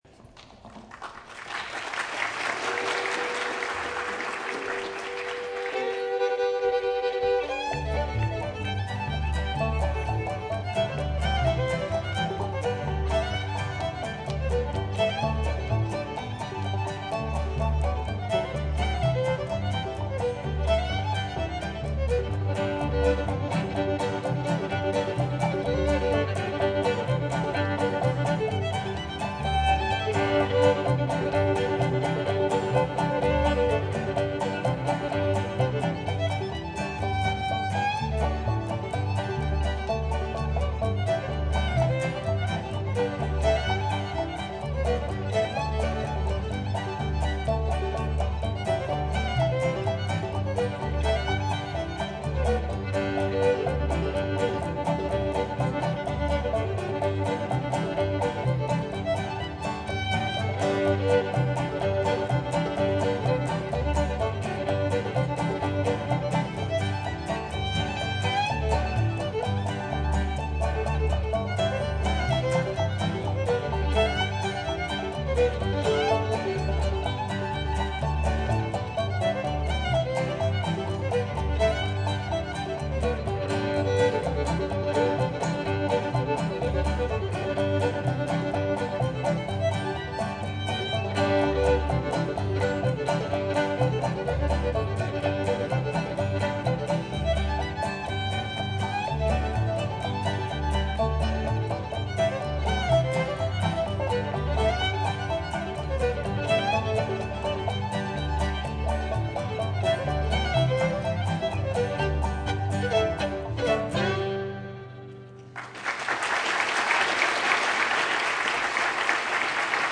fiddle. Many others.